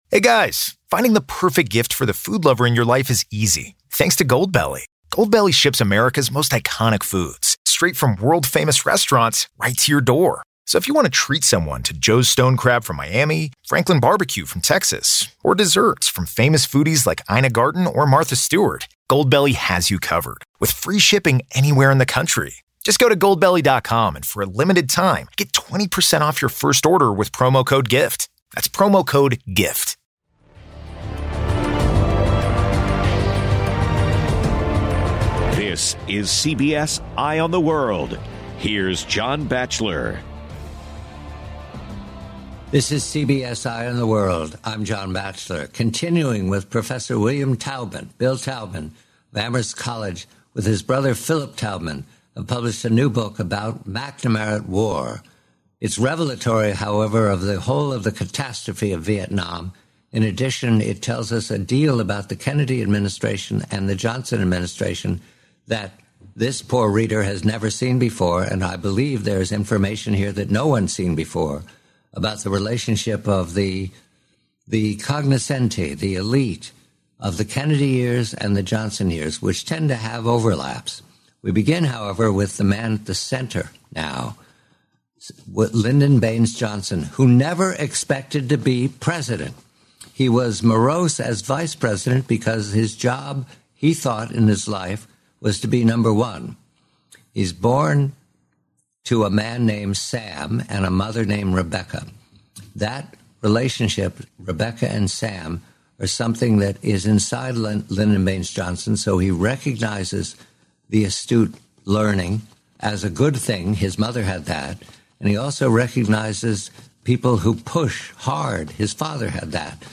Professor William Taubman discusses Robert McNamara's complicated role during the LBJ years. McNamara enabled the Vietnam War escalation, notably misrepresenting the Gulf of Tonkin incidents to Congress.